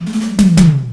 FILLTOMEL2-R.wav